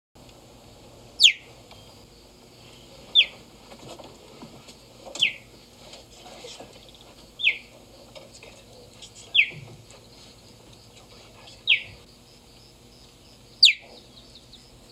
8. Which animal chirps like this?
Were you surprised to learn a cat could sound like a bird?
Cheetahs purr, growl, hiss and chirp.
cheetah.mp3